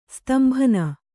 ♪ stambhana